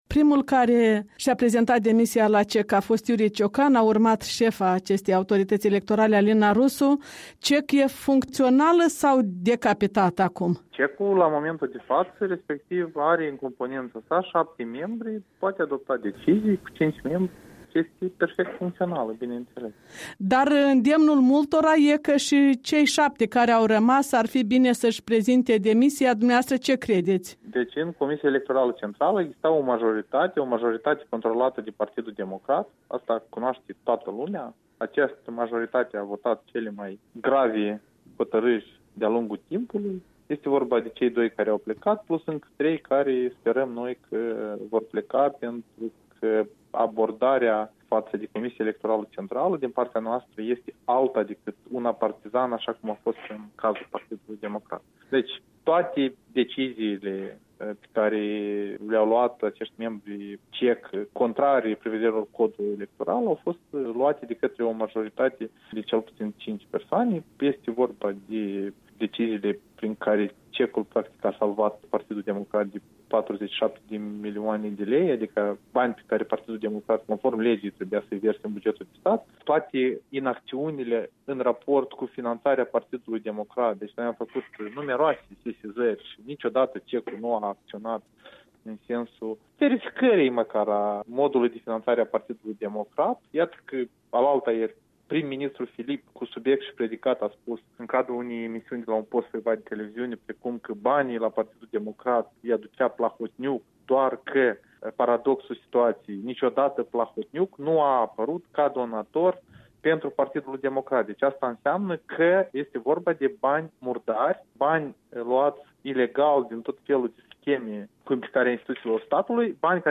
Interviu cu şeful Comisiei parlamentare juridice, pentru numiri şi imunităţi, deputatul Blocului ACUM, în fracțiunea PAS.
Interviu cu Sergiu Litvinenco, şeful Comisiei parlamentare juridice, pentru numiri şi imunităţi